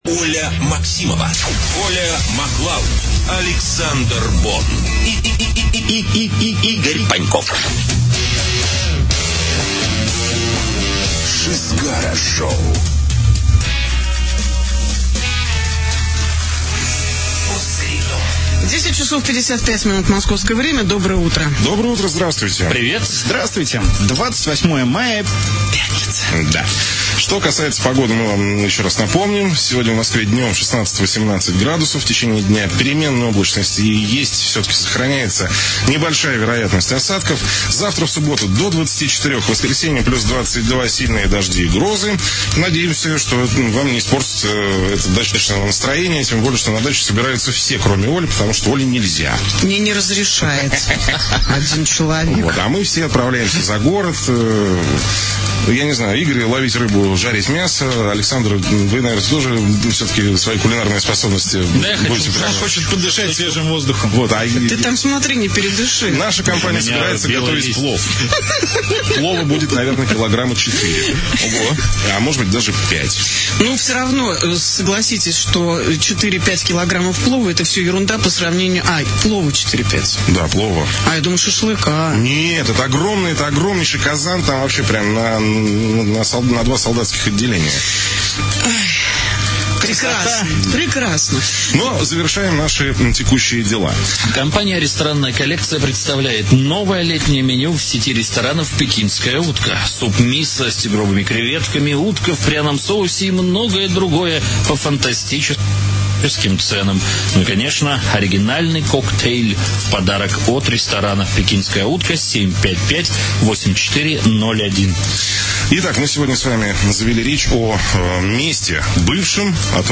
Утреннее шоу "Шызгара шоу". Последний эфир. 28 мая 2010 года. Запись эфира.